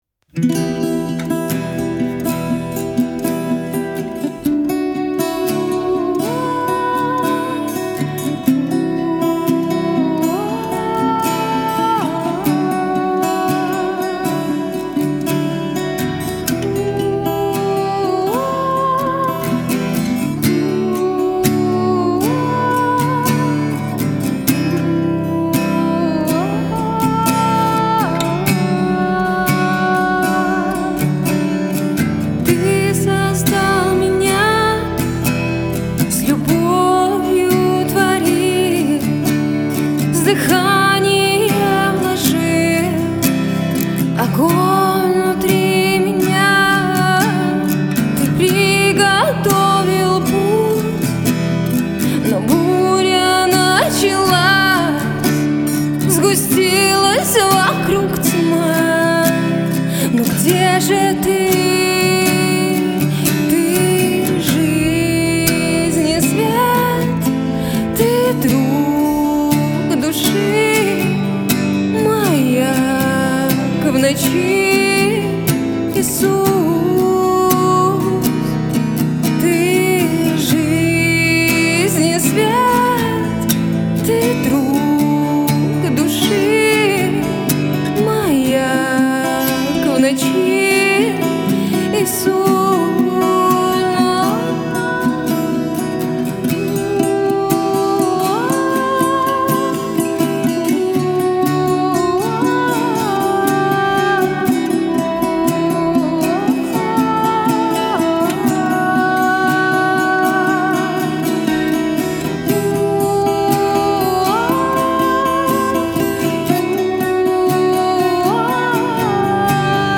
песня
Em  D C D
283 просмотра 351 прослушиваний 14 скачиваний BPM: 68